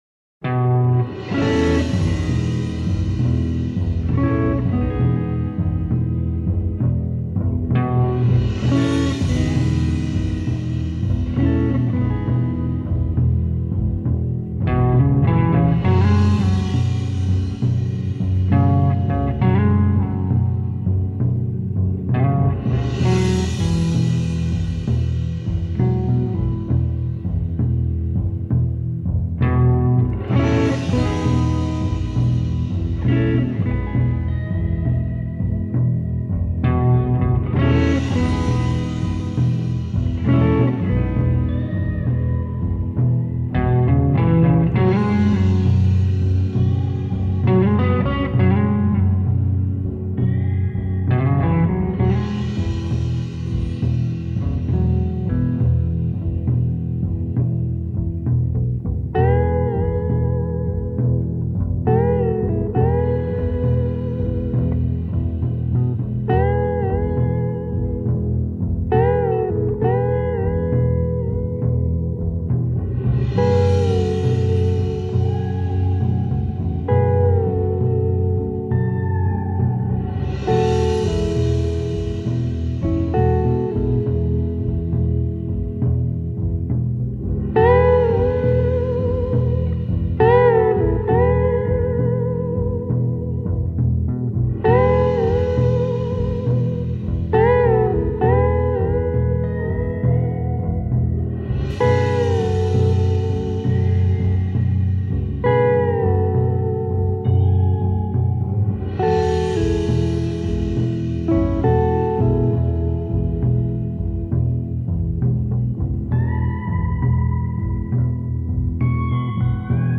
гитарная инструментальная пьеса